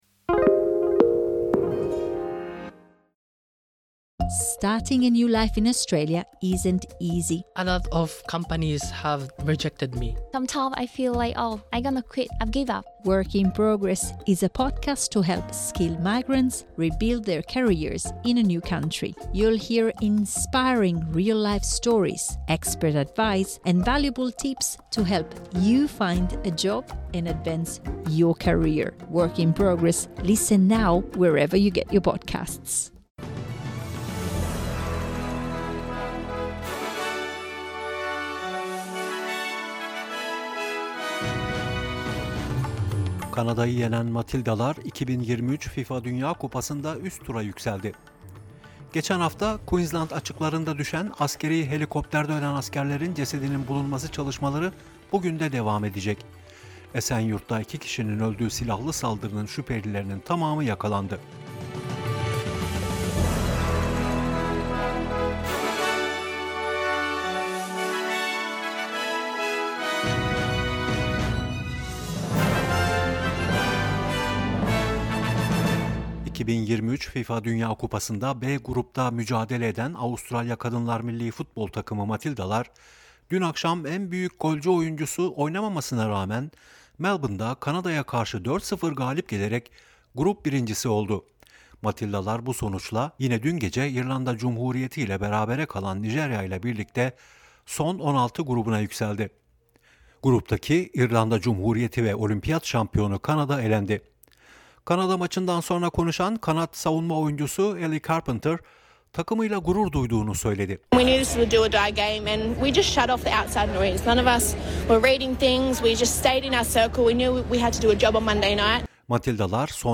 SBS Türkçe Haber Bülteni